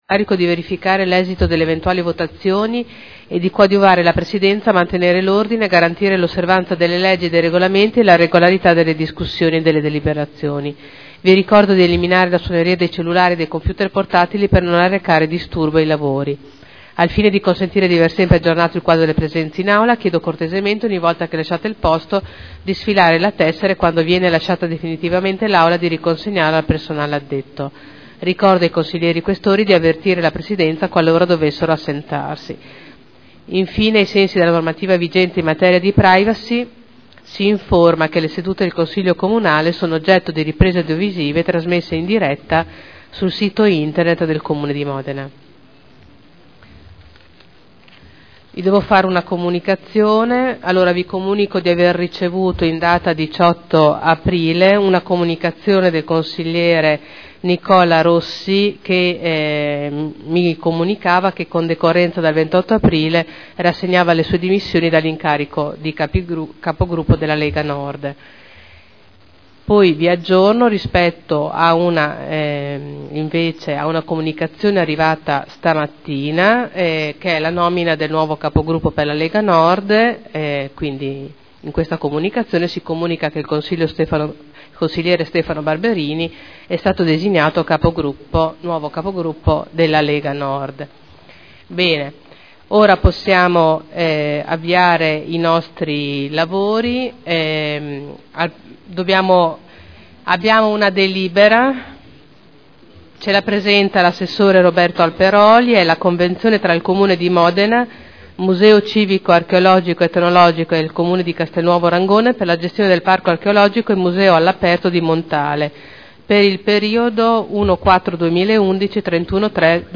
Dopo le interrogazioni il Presidente Caterina Liotti apre la seduta del Consiglio Comunale.